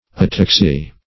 ataxy - definition of ataxy - synonyms, pronunciation, spelling from Free Dictionary
Ataxia \A*tax"i*a\, Ataxy \At"ax*y\, n. [NL. ataxia, Gr. ?, fr.